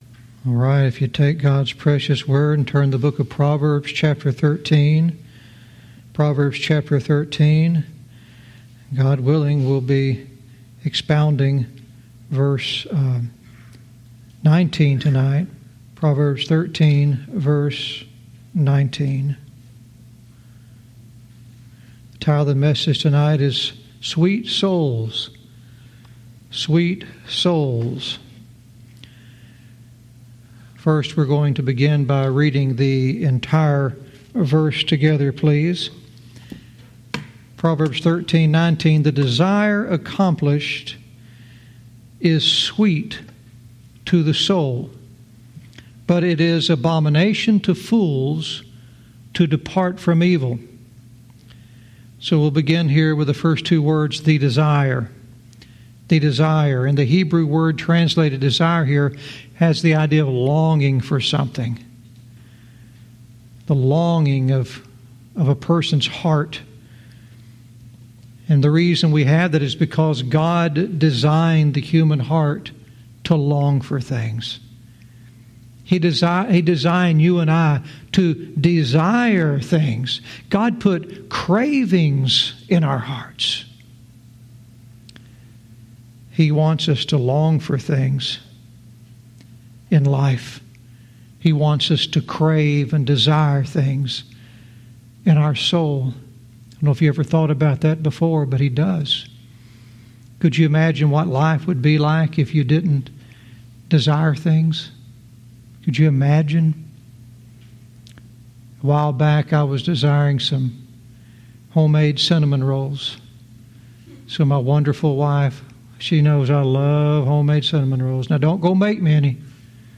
Verse by verse teaching - Proverbs 13:19 "Sweet Souls"